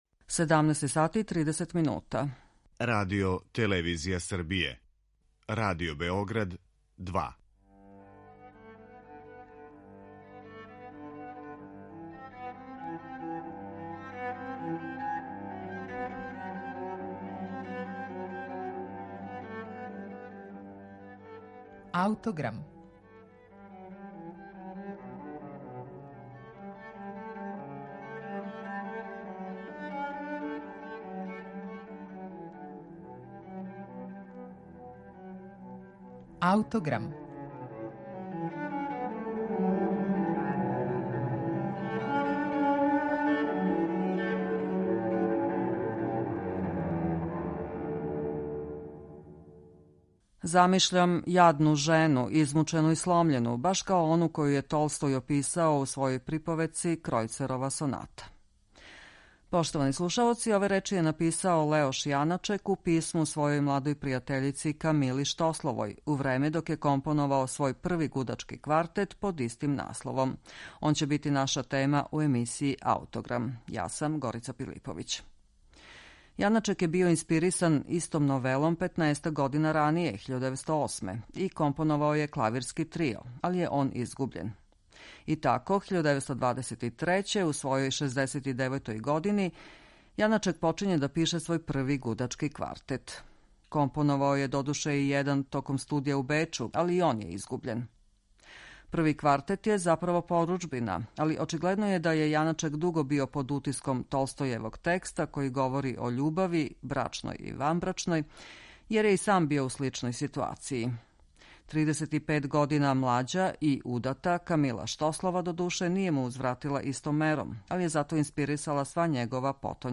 Јаначеков Први гудачки квартет